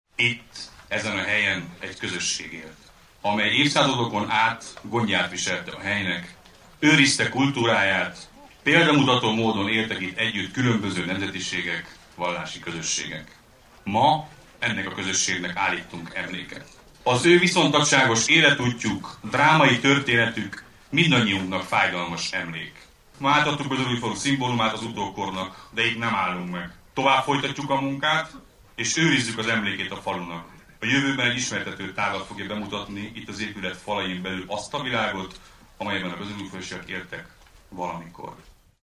Csibi Attila Zoltánt, a templomépítést kezdeményező Erdőszentgyörgy polgármesterét hallják.